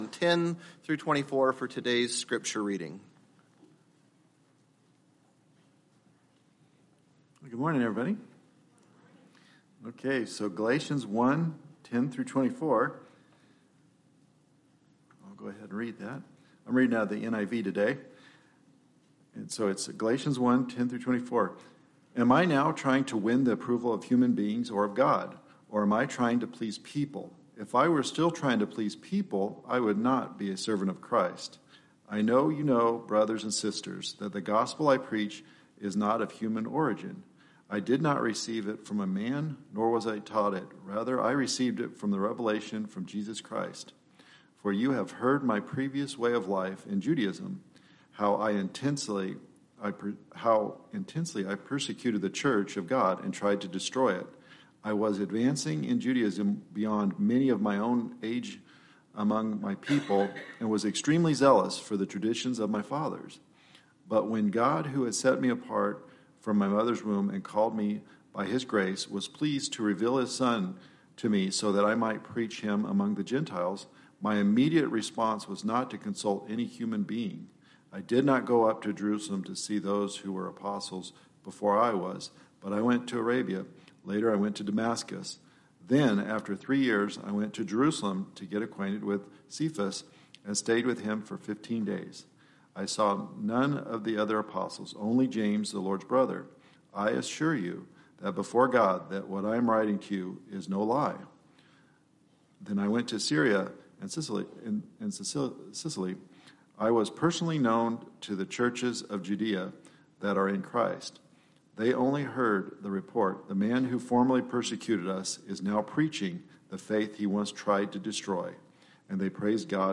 Watch or search our on-demand video library for past sermons from Kuna Baptist Church in Kuna, Idaho.